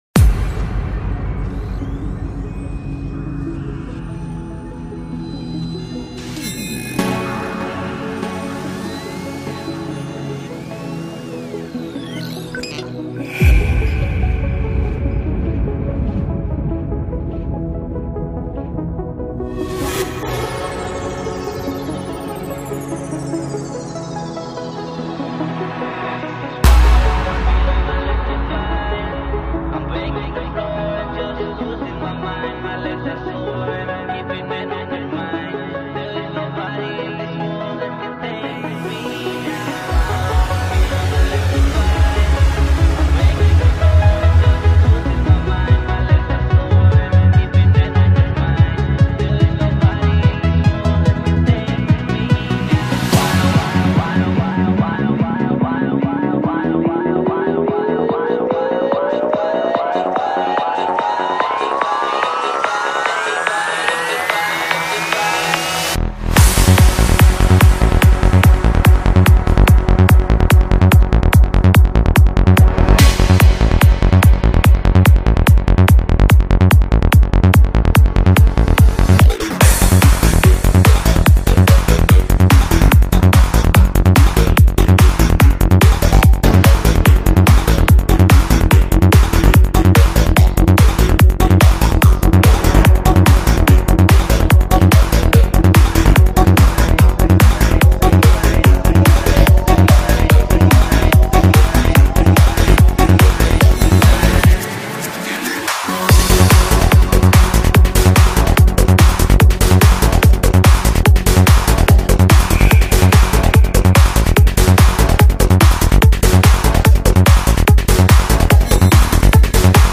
trance psicodélico